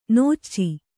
♪ nōcci